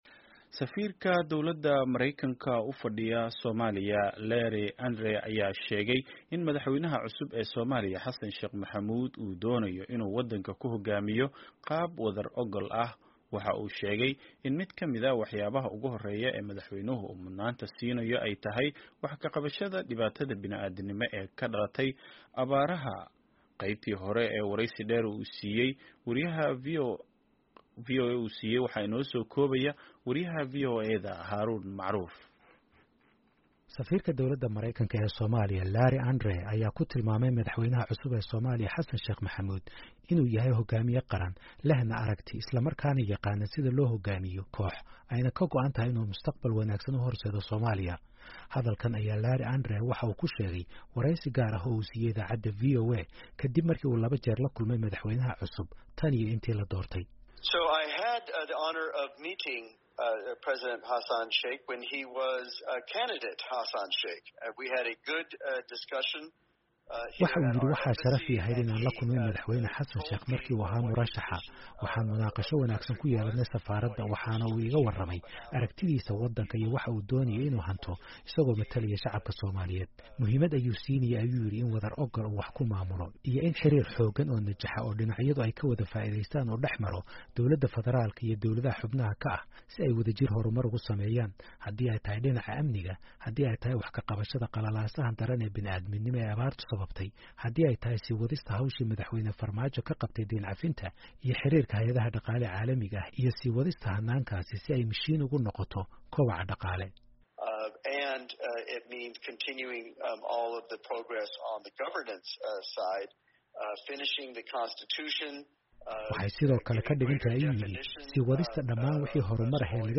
Wareysi: Safiirka Mareykanka ee Soomaaliya Larry Andre (Qeybtii koowaad)